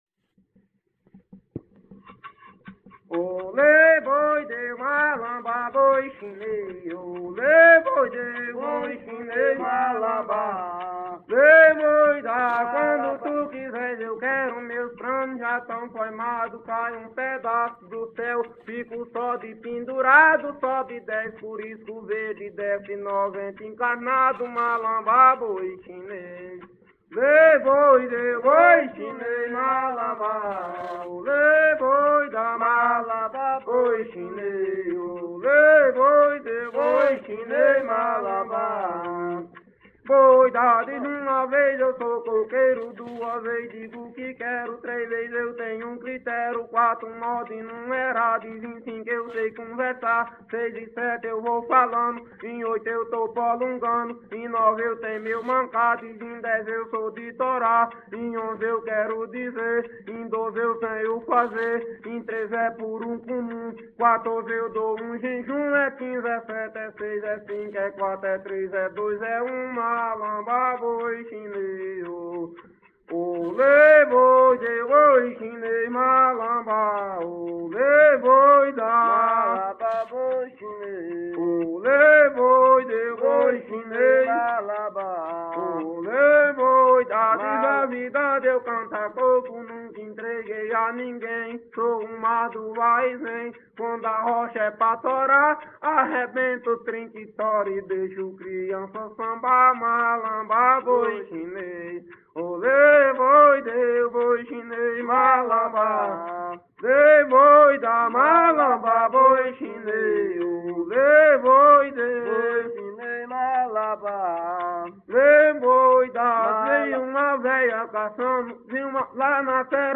Coco parcelado – “”Malambá o boi chinês”” - Acervos - Centro Cultural São Paulo